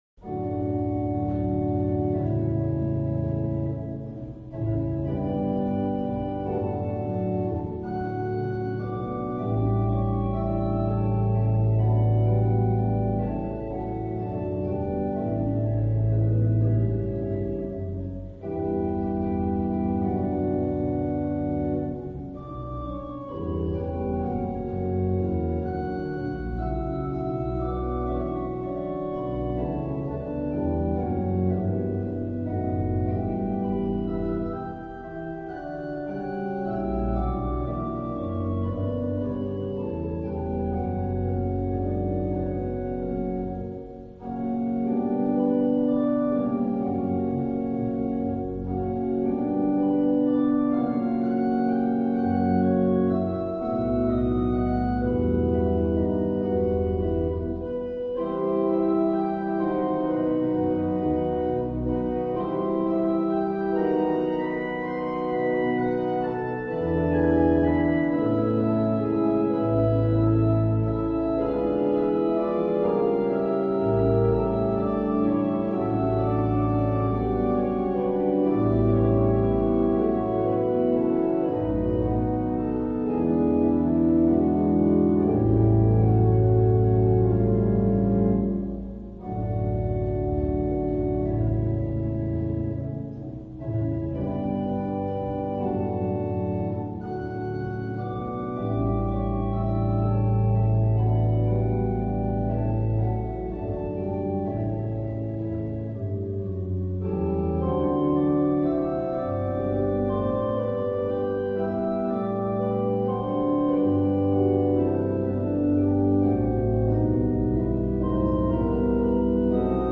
Het Van Dam-orgel
Oorspronkelijk is dit instrument gebouwd als tweeklaviersorgel met aangehangen pedaal door de firma Van Dam, orgelbouwers te Leeuwarden.
Orgel-1.mp3